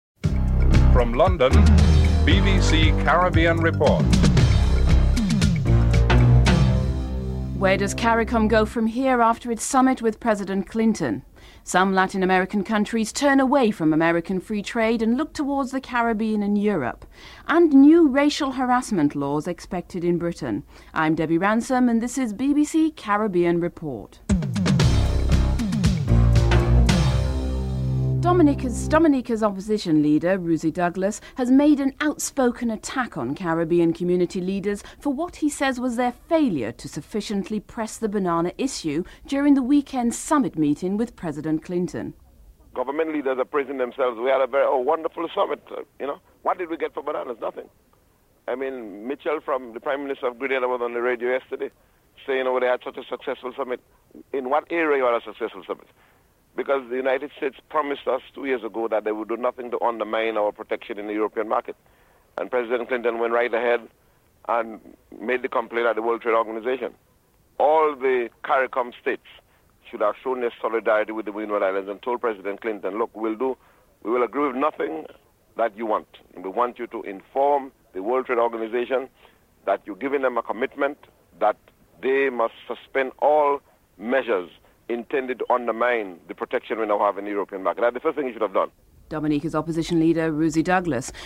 1. Headlines (00:00-00:27)
4. New racial harassment laws expected in Britain. British Member of Parliament, Bernie Grant is interviewed.